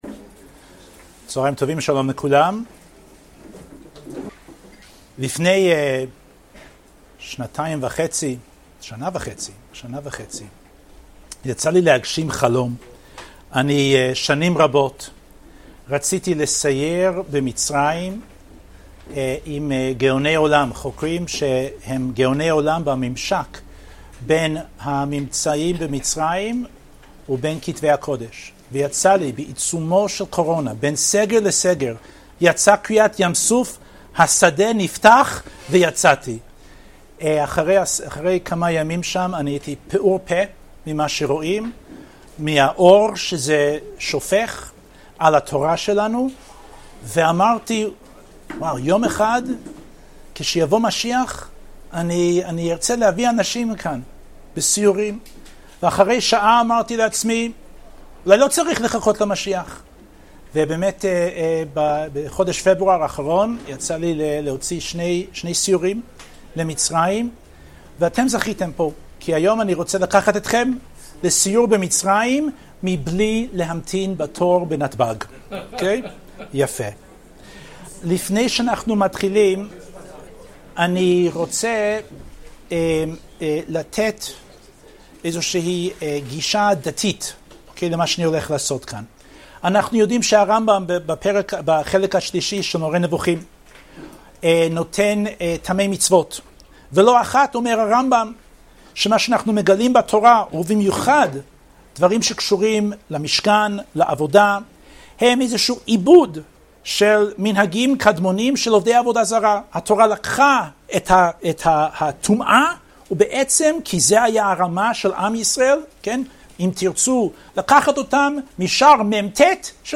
השיעור באדיבות אתר התנ"ך וניתן במסגרת ימי העיון בתנ"ך של המכללה האקדמית הרצוג תשפ"ב